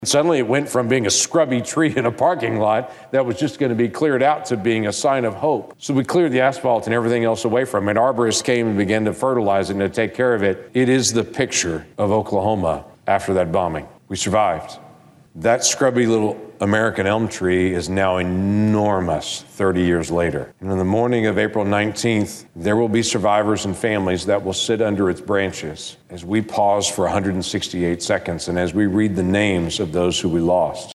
Oklahoma Senator James Lankford recently spoke to his colleagues on the Floor and said at the time of the attack, there was a small shrub across the street that many thought would die.